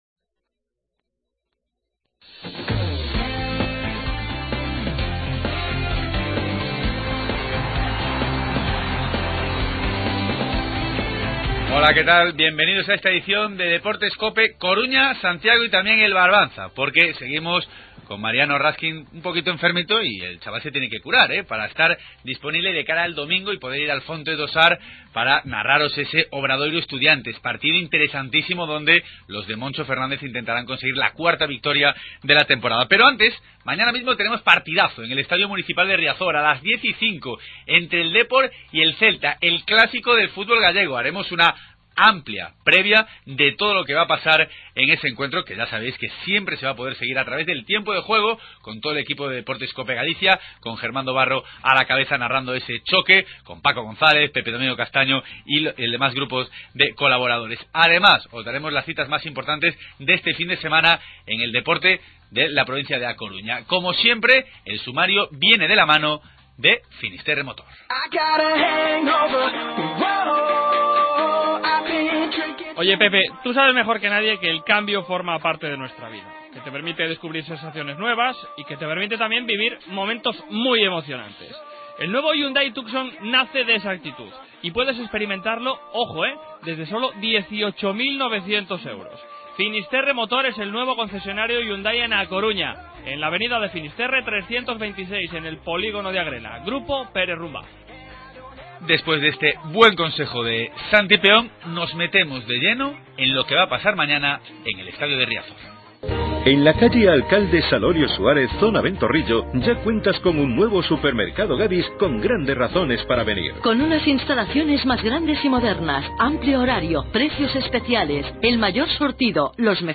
Previa del Depor-Celta. Analizamos todo lo que rodea al clásico del fútbol gallego. Escuchamos al entrenador blanquiazul Víctor Sánchez del Amo y al central Sidnei.